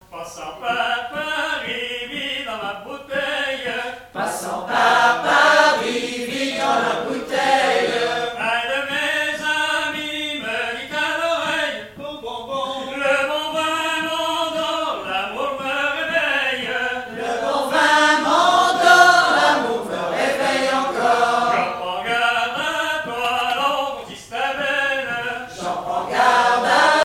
7e festival du chant traditionnel : Collectif-veillée
Pièce musicale inédite